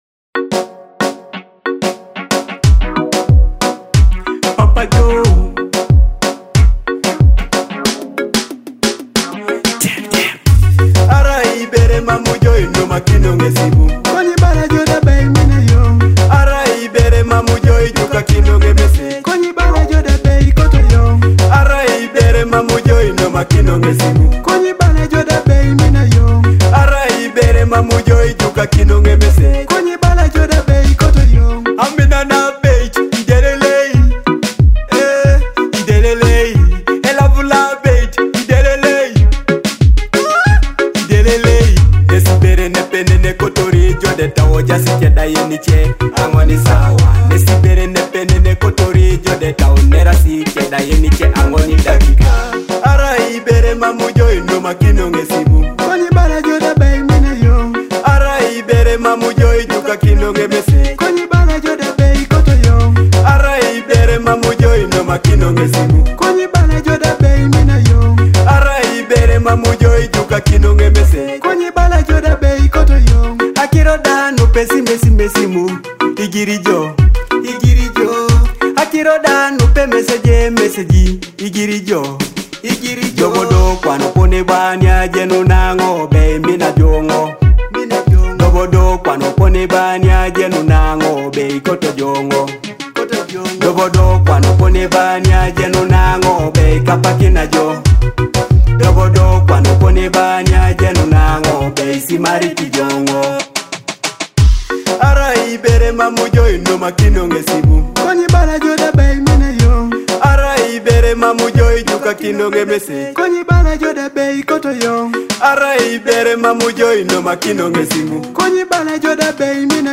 a fresh Teso hit